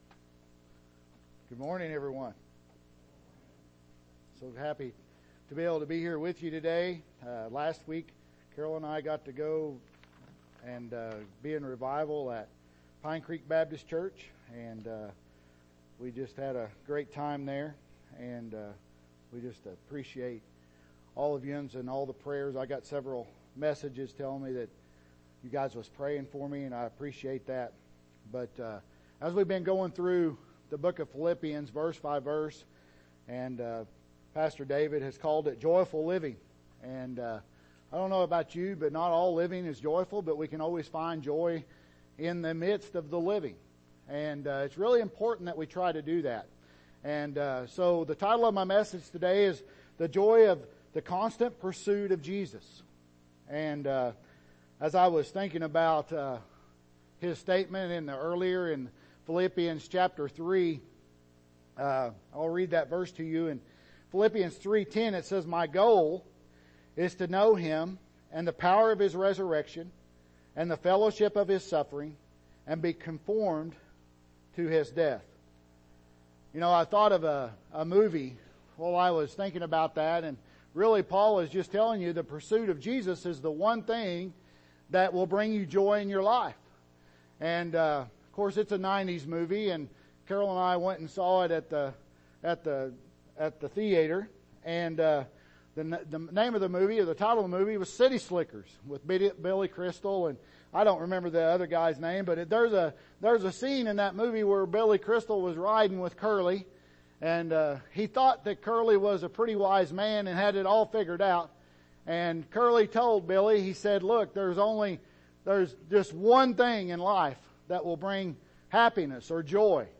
Passage: Philippians 3:12-21 Service Type: Morning Service